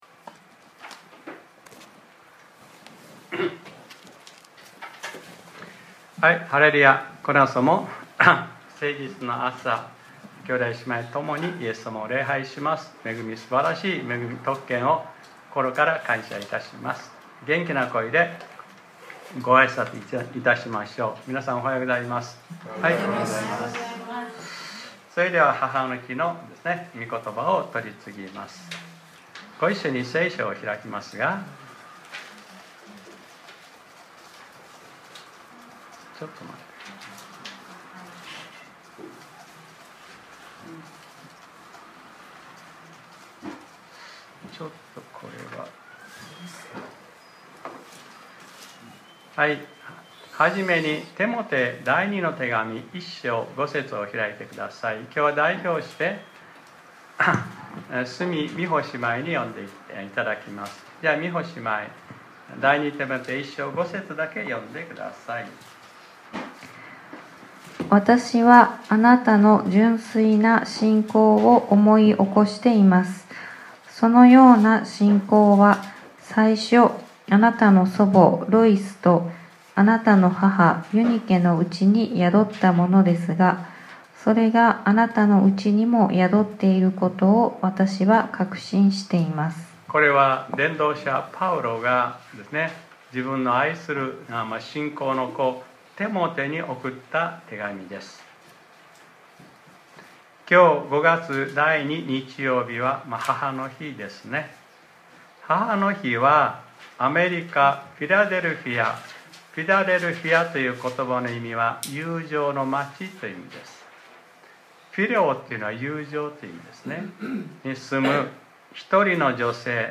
2025年05月11日（日）礼拝説教『 母の日：ロイス、ユニケ、テモテ 』